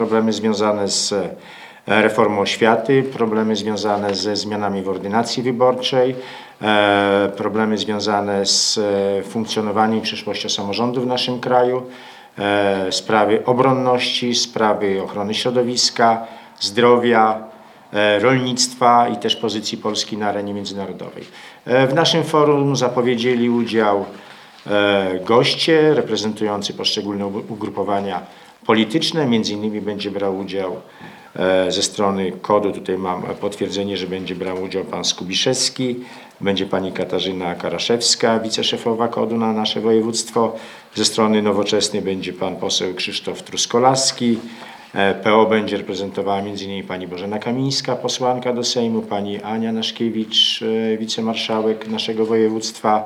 Jak zaznaczył Cezary Cieślukowski spotkanie jest otwarte dla wszystkich zainteresowanych. Podczas konferencji przedstawił szeroki wachlarz spraw, jakie zostaną poruszone podczas spotkania.